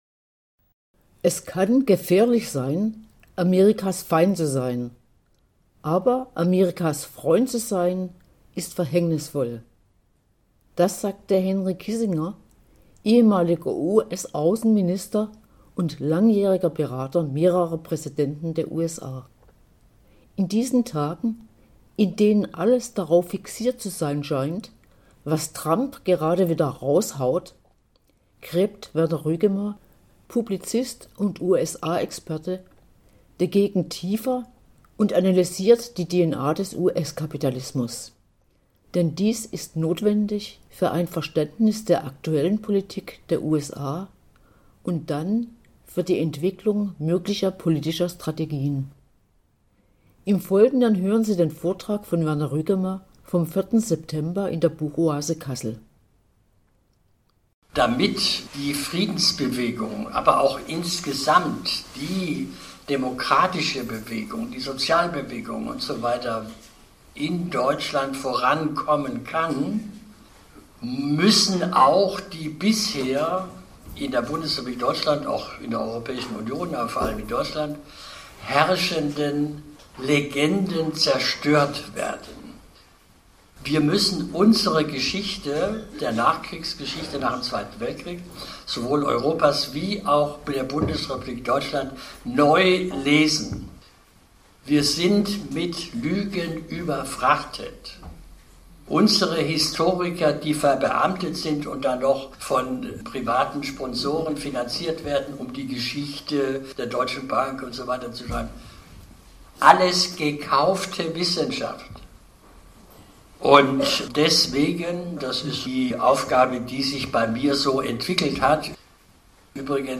Im Vortrag werden viele belegte Beispiele von großer historischer Bedeutung zur Sprache kommen. Der Weg der USA wird skizziert, vom Sklavenstaat zu „God’s own Country“ bis hin zu der „America First“-Politik, die wir gerade hautnah erleben.